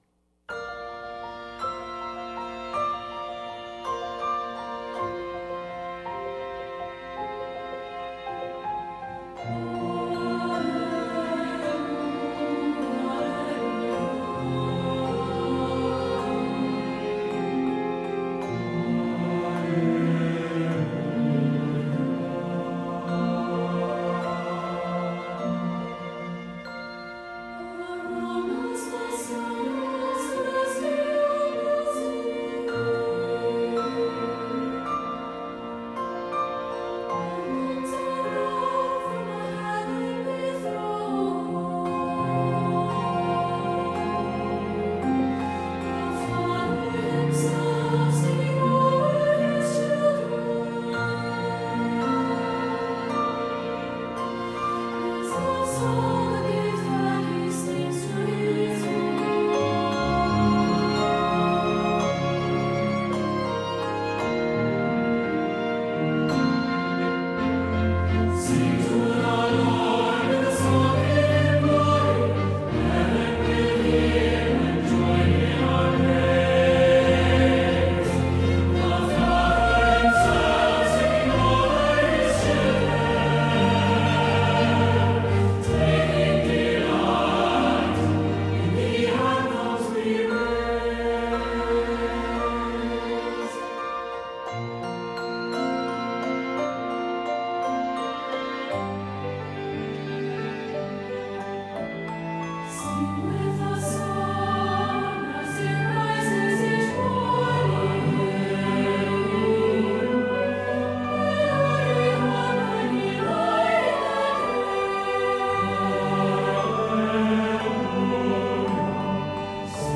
Hopeful and uplifting